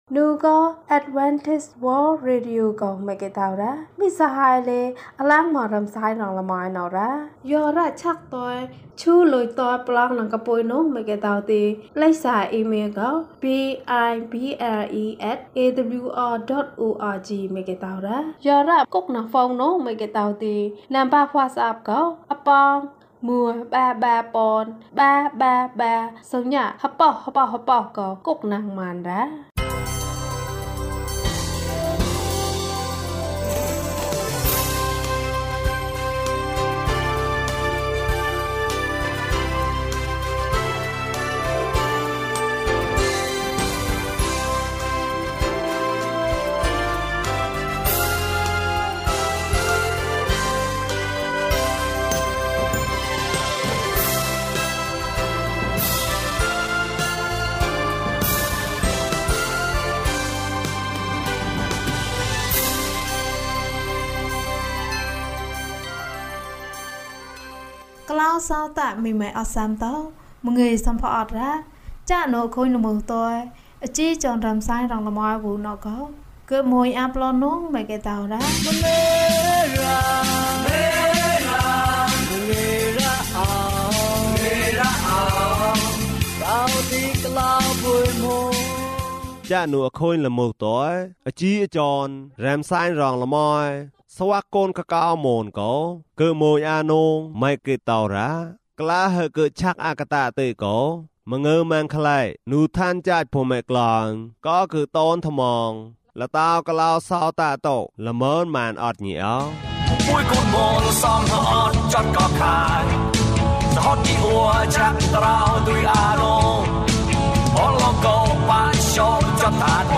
သခင်ယေရှု သင့်ကိုချစ်တယ်။၀၂ ကျန်းမာခြင်းအကြောင်းအရာ။ ဓမ္မသီချင်း။ တရားဒေသနာ။